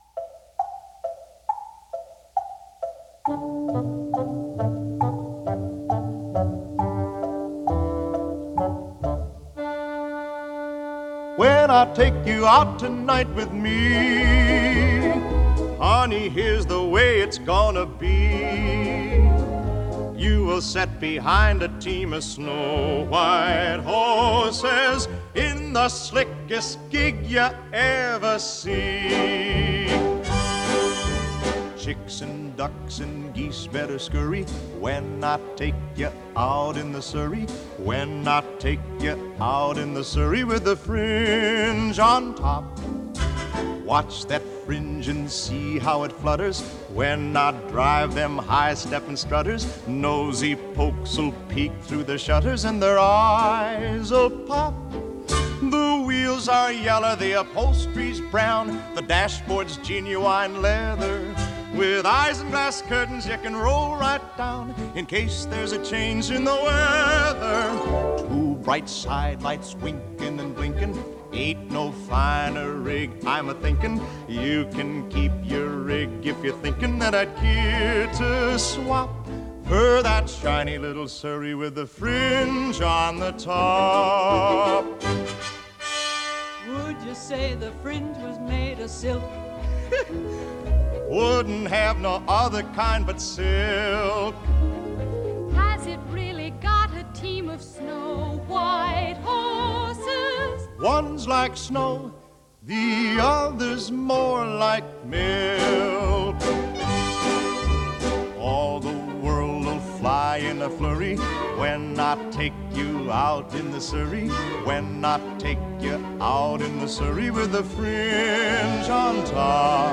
from the film version of the musical